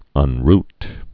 (ŭn-rt, -rt)